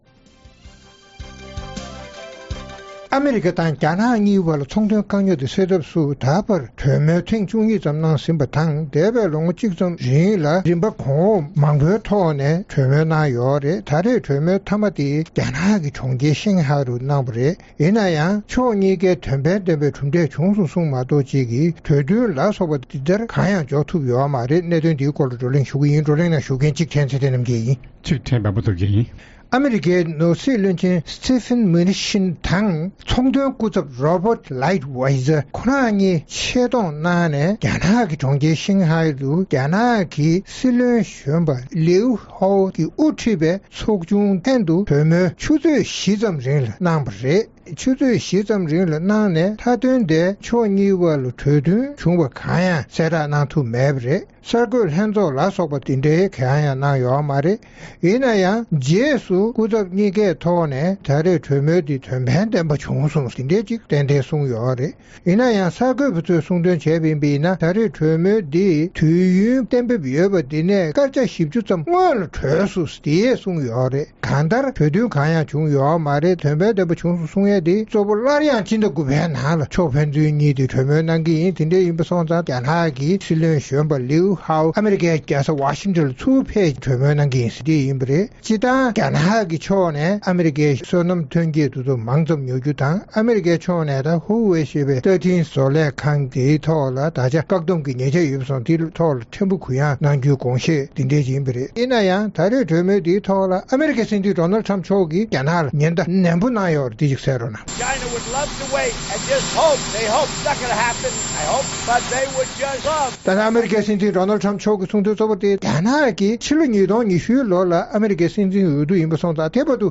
རྩོམ་སྒྲིག་པའི་གླེང་སྟེགས་ཞེས་པའི་ལེ་ཚན་ནང་། ཨ་རི་དང་རྒྱ་ནག་གི་ཚོང་དོན་སྐུ་ཚབ་རྣམས་རྒྱ་ནག་ཏུ་ཚོང་དོན་གྲོས་མོལ་གནང་ཡང་གྲུབ་འབྲས་བྱུང་མེད་པ་དང་། ཨ་རིའི་སྲིད་འཛིན་གྱིས་སླར་ཡང་རྒྱ་ནག་གི་དངོས་ཟོག་ལ་སྒོ་ཁྲལ་འཕར་མ་རྒྱག་རྒྱུའི་ཉེན་བརྡ་བཏང་བ་བཅས་ཀྱི་སྐོར་རྩོམ་སྒྲིག་འགན་འཛིན་རྣམ་པས་བགྲོ་གླེང་གནང་བ་གསན་རོགས་གནང་།